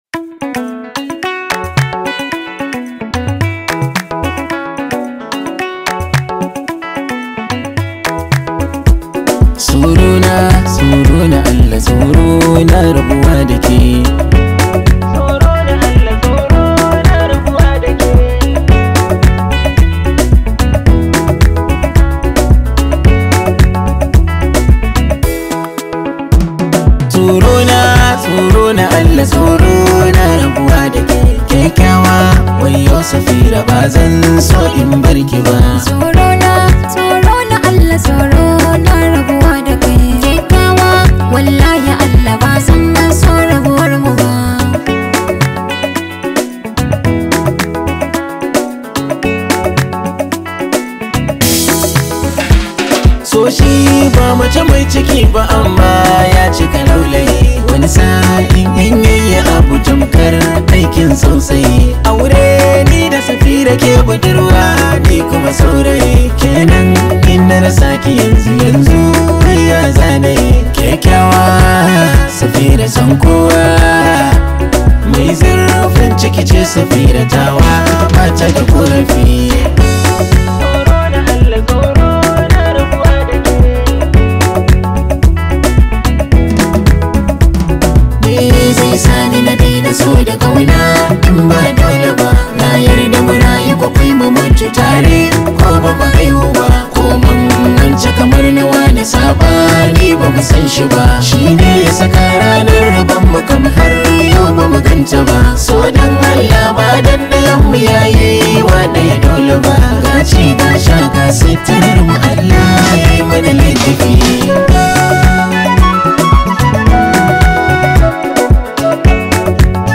high vibe hausa song